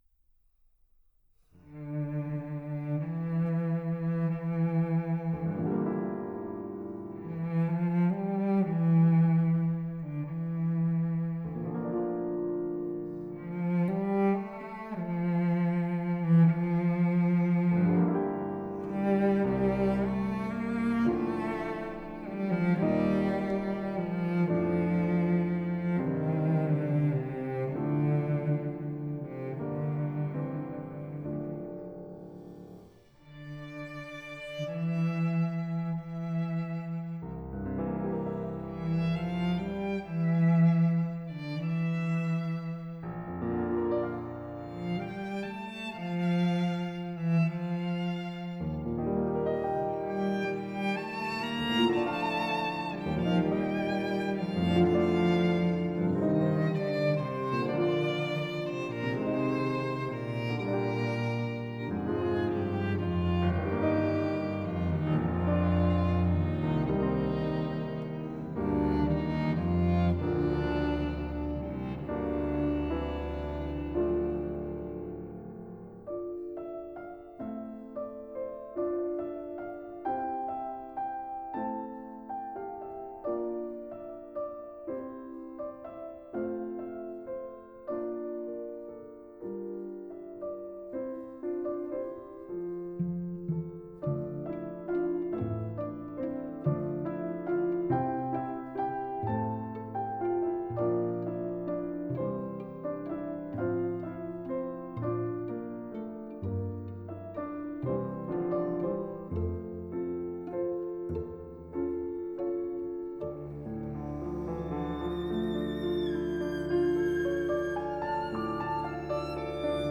موسیقی کلاسیک از ژوزف یونگن ، Joseph Jongen _ piano trio in B minor II andante molto sostenuto